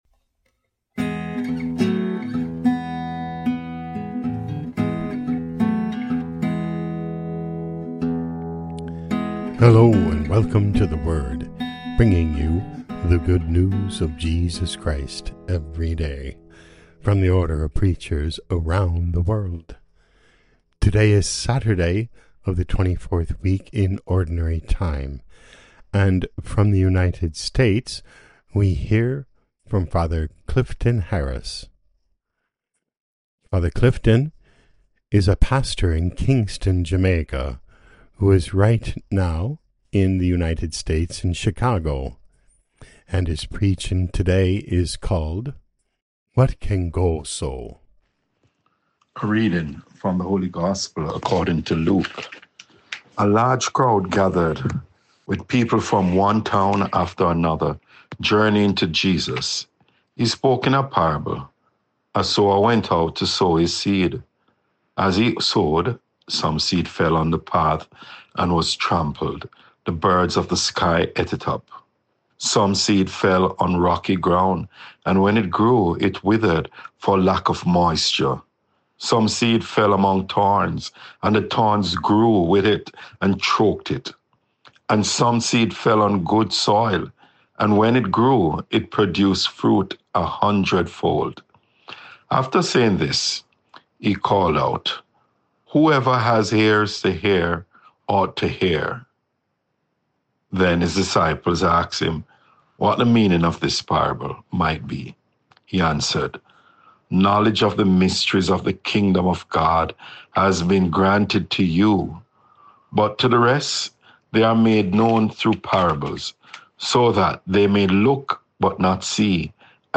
Podcast: Play in new window | Download For 20 September 2025, Saturday of week 24 in Ordinary Time, based on Luke 8:4-15, sent in from Chicago, IL USA.
Preaching